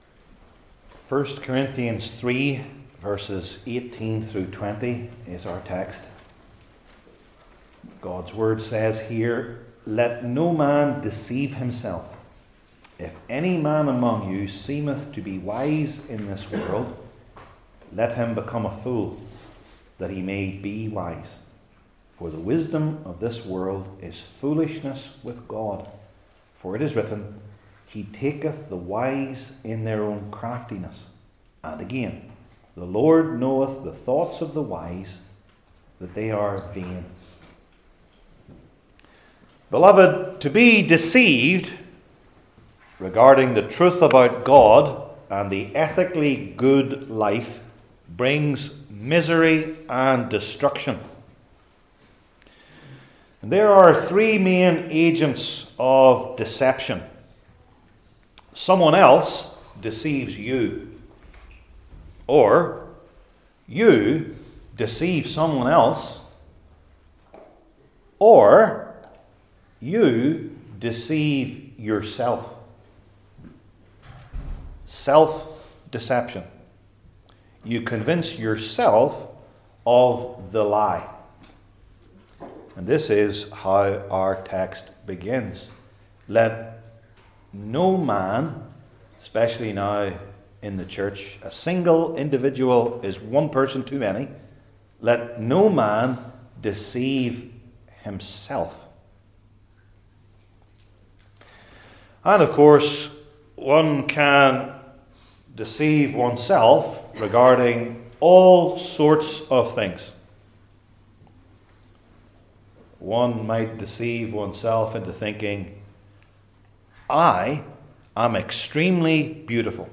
I Corinthians 3:18-20 Service Type: New Testament Sermon Series I. The Biblical Meaning II.